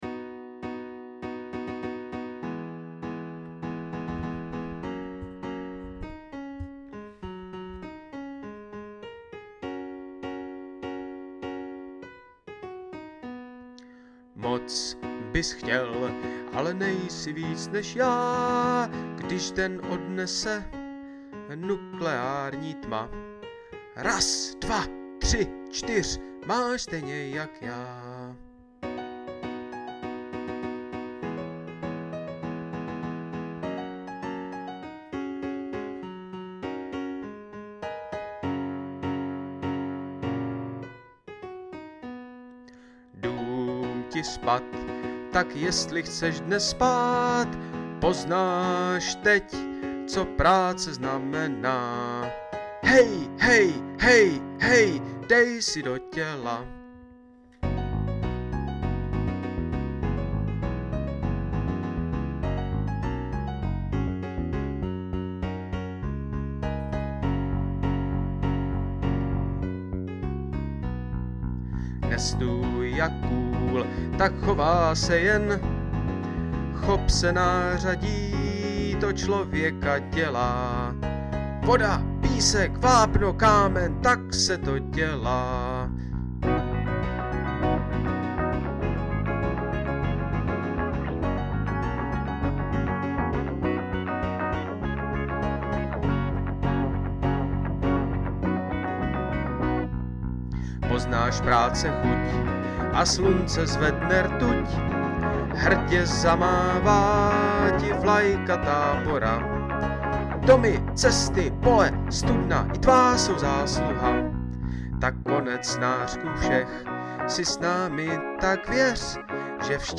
Budování tábora - peónská píseň